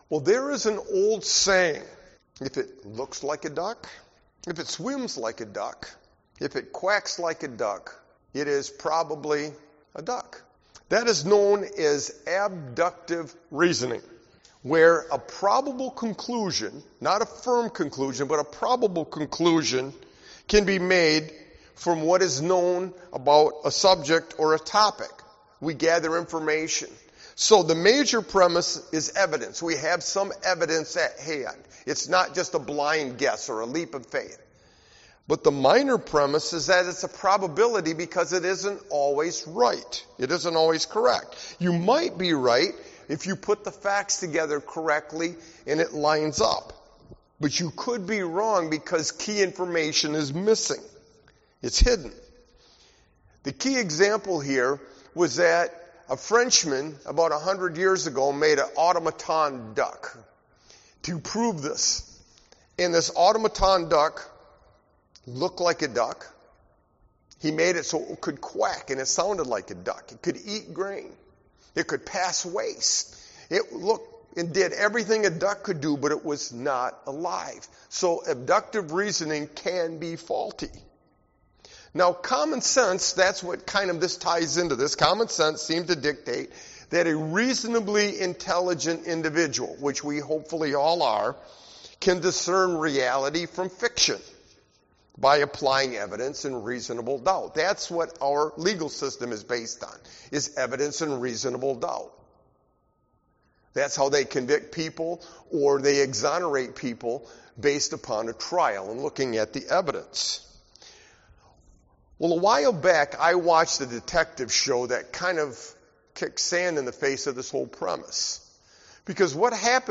Sermon-Light-and-Darkness-LXXVII-7322.mp3